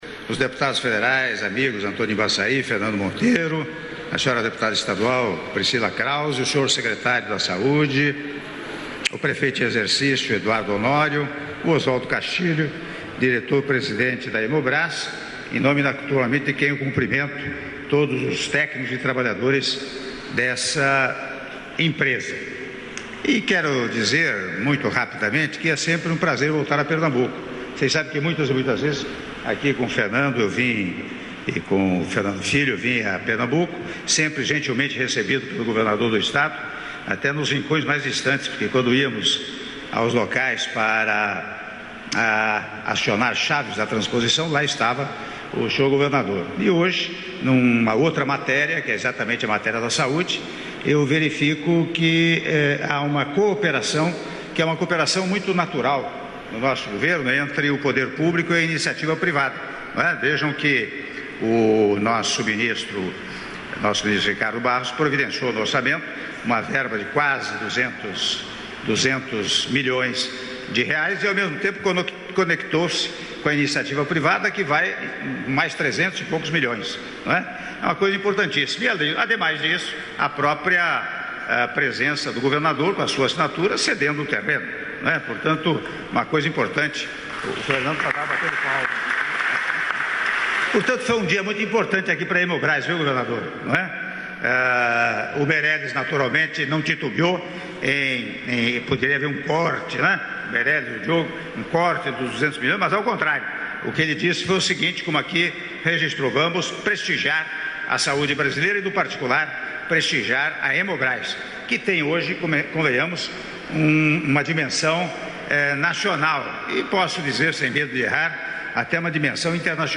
Áudio do discurso do Presidente da República, Michel Temer, durante Cerimônia de Anúncio de Investimentos para Retomada das Obras da Fábrica da Hemobrás - (04min42s) - Goiana/PE